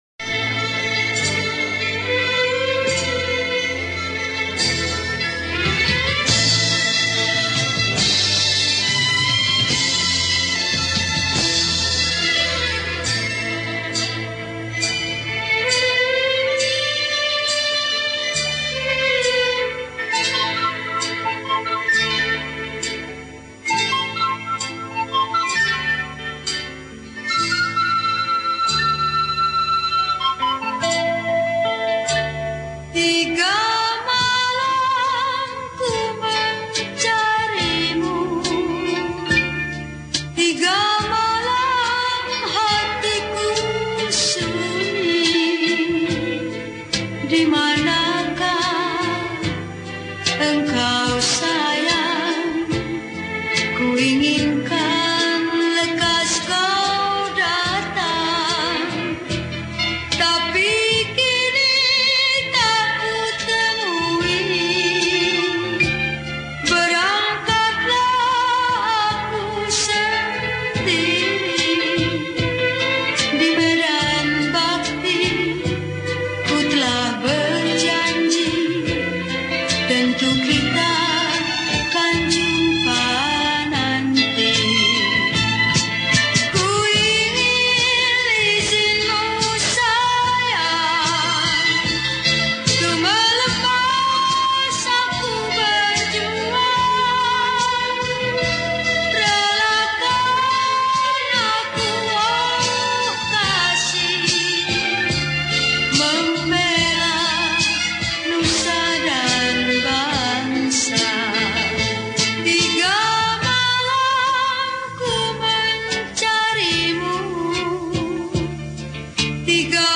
Lagu Patriotik
Solo Recorder